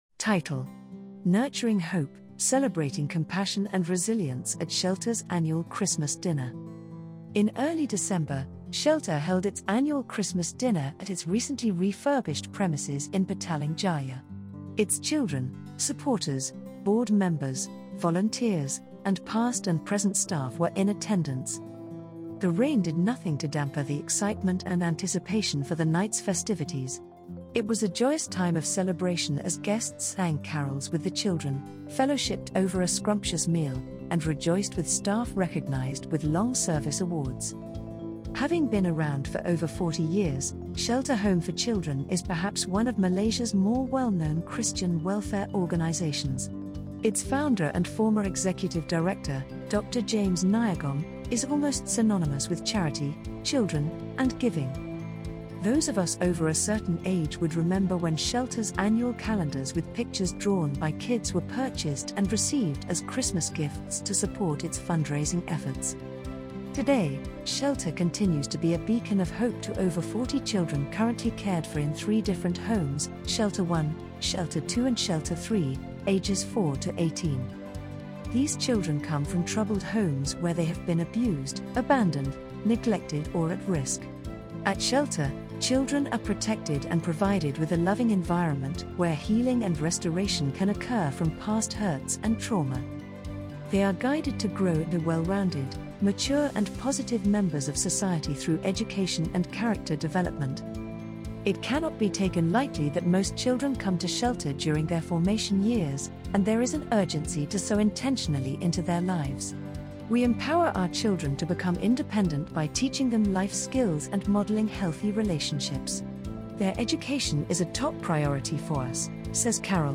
Audio Version: Nurturing Hope: Celebrating Compassion and Resilience at Shelter’s Annual Christmas Dinner